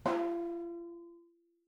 vibraring_v1_rr1.wav